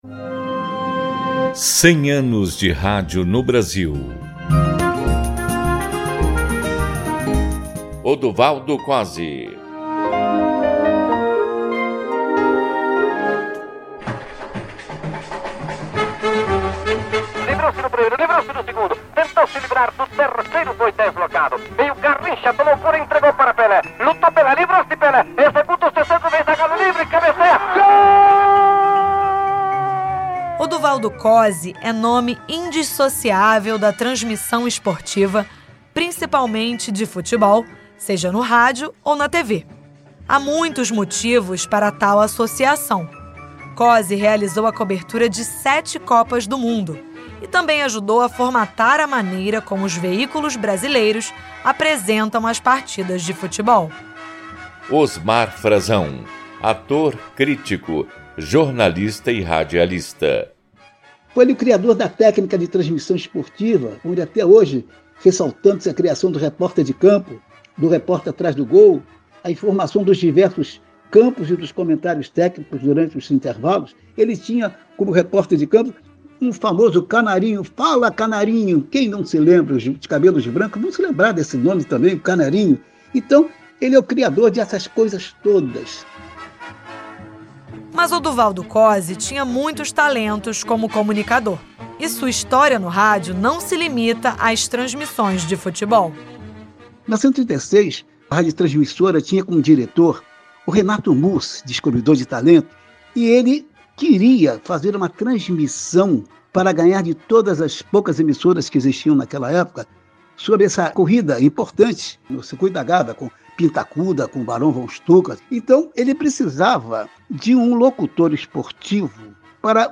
Até 7 de setembro, a Rádio MEC vai produzir e transmitir, diariamente, interprogramas com entrevistas e pesquisas de acervo sobre diversos aspectos históricos relacionados ao veículo.